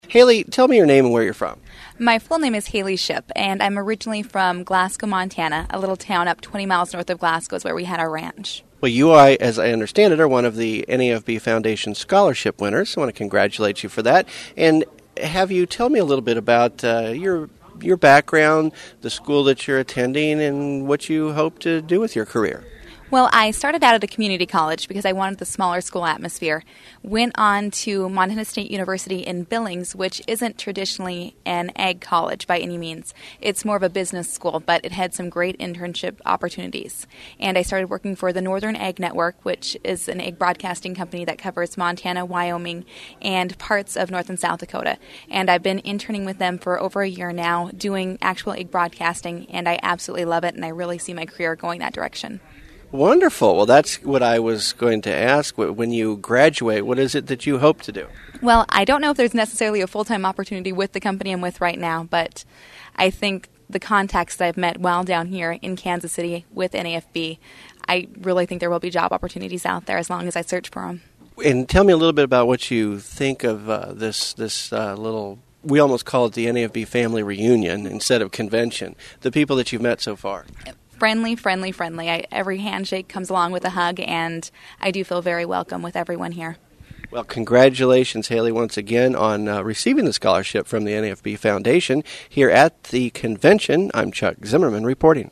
NAFB Convention Photo Album